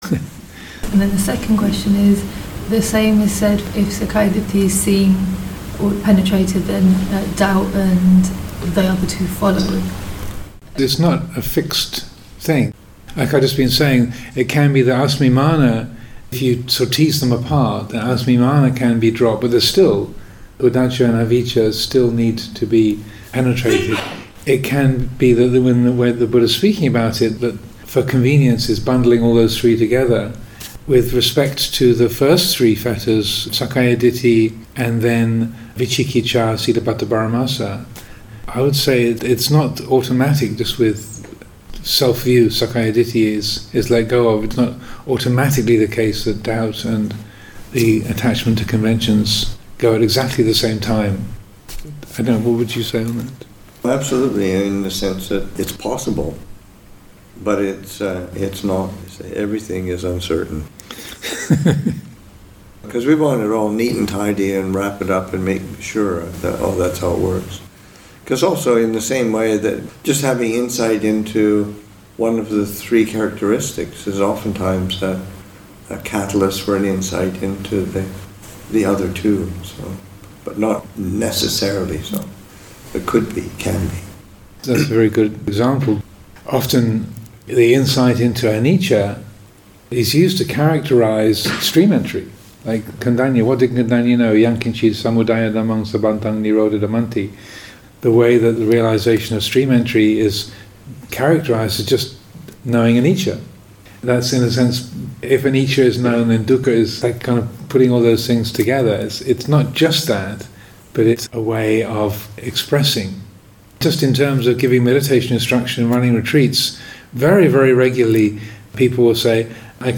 2014 Thanksgiving Monastic Retreat, Session 2, Excerpt 1